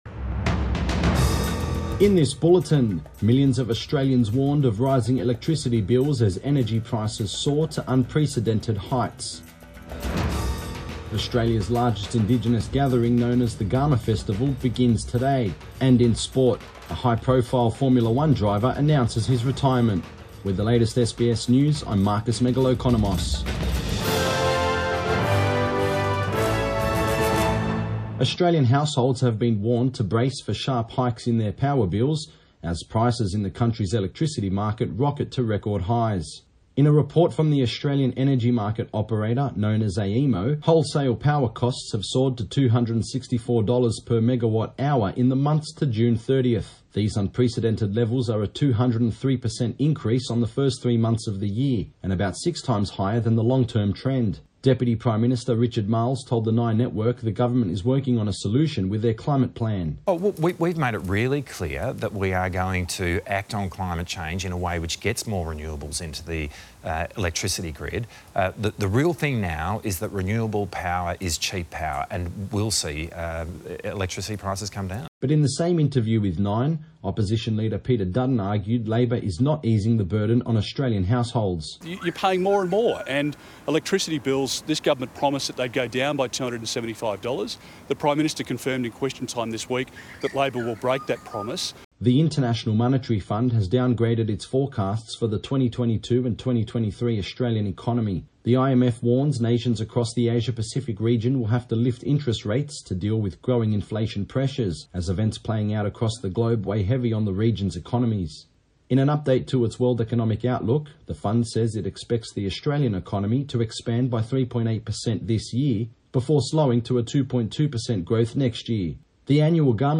Midday bulletin 29 July 2022